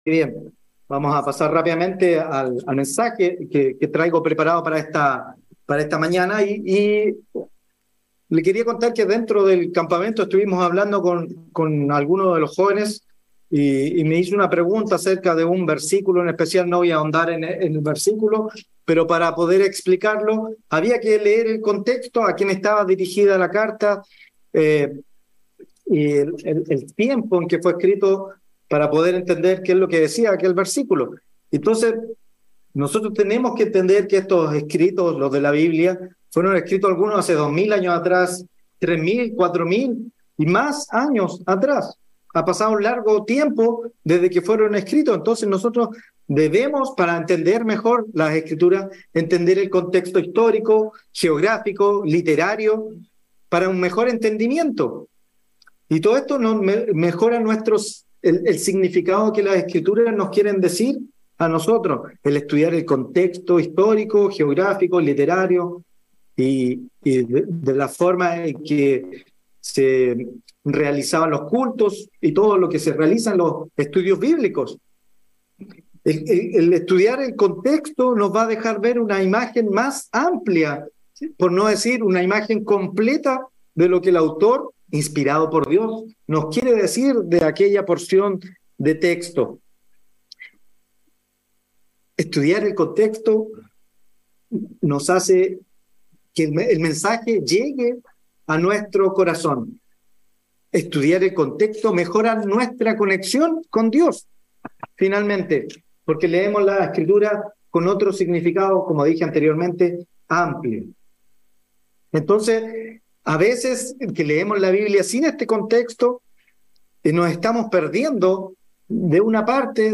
Given in Temuco